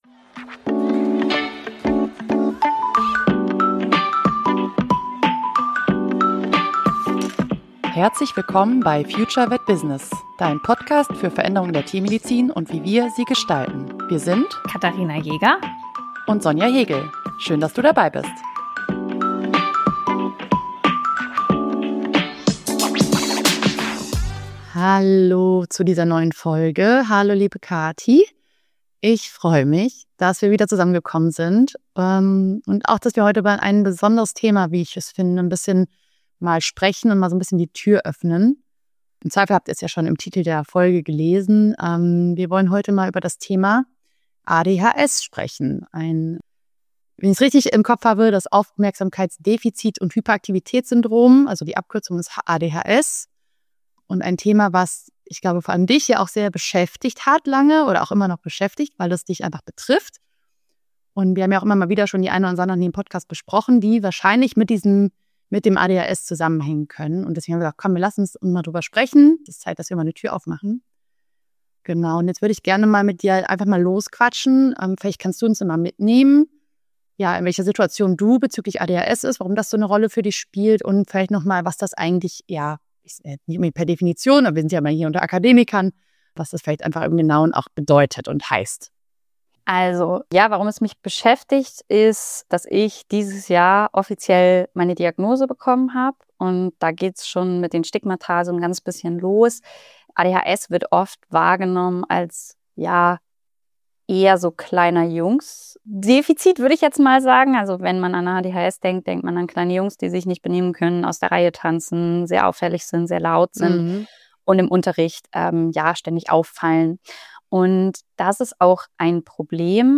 Welche Strategien helfen, den Praxisalltag und das Berufsleben trotzdem zu meistern? Ein ehrliches Gespräch über Scham, Erleichterung, Selbstakzeptanz – und darüber, warum ADHS auch eine Stärke sein kann.